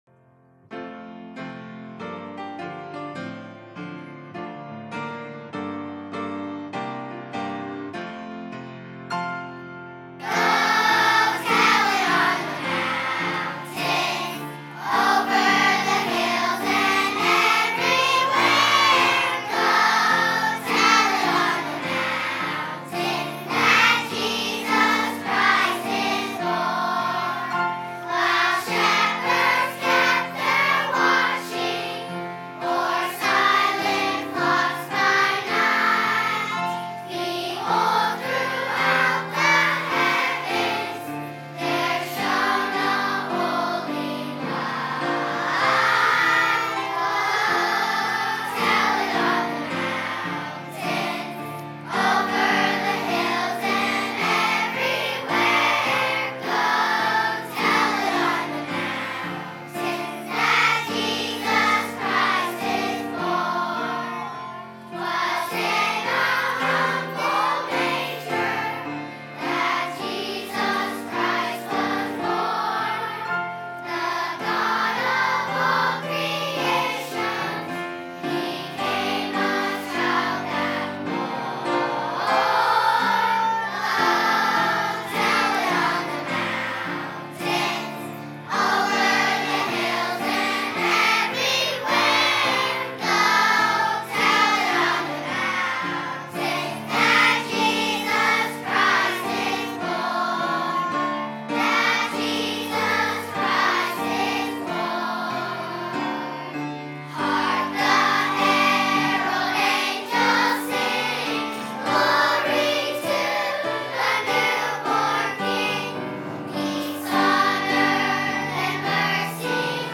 by VBC Children's Choir | Verity Baptist Church
Kids-Choir-mp3.mp3